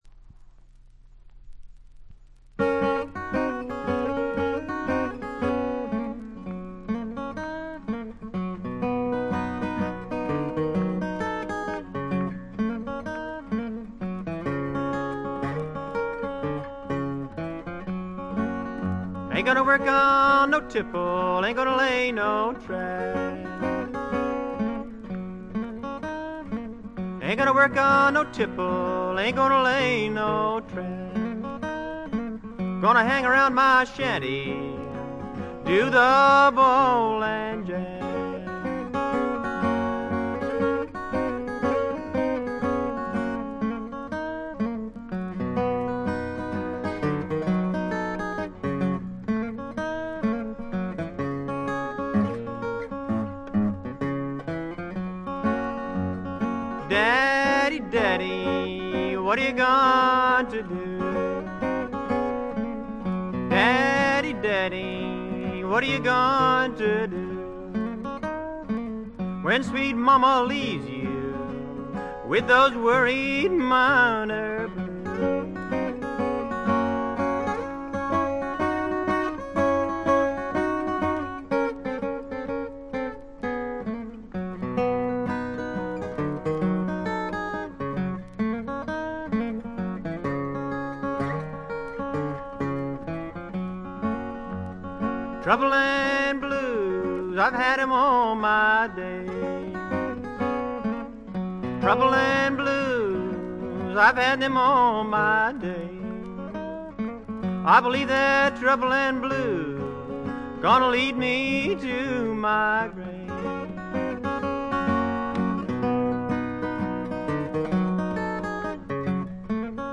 原初のブルーグラスの哀愁味あふれる歌が素晴らしいです！
試聴曲は現品からの取り込み音源です。